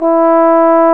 Index of /90_sSampleCDs/Roland L-CDX-03 Disk 2/BRS_Trombone/BRS_Tenor Bone 2